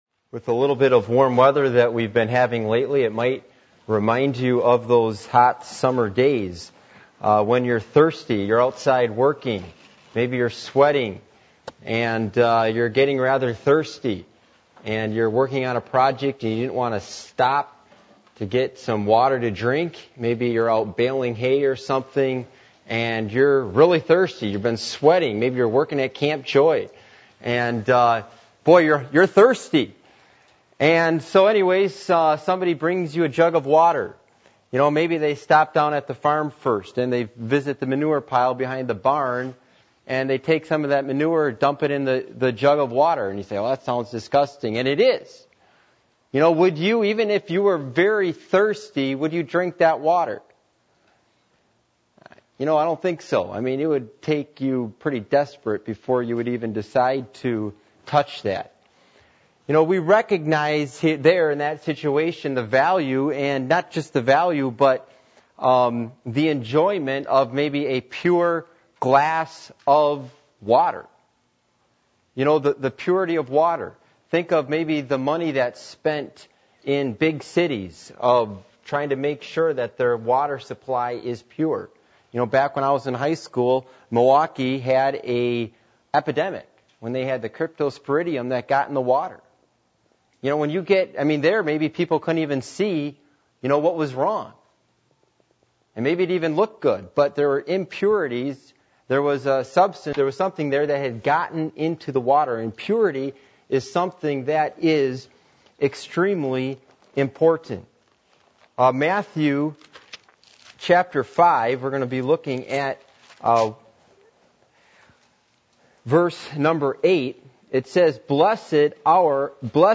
Passage: Matthew 5:7-9 Service Type: Midweek Meeting %todo_render% « The Cycles Of The Period Of Judges Christian Home Series